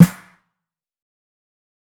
Detox Snare 1.wav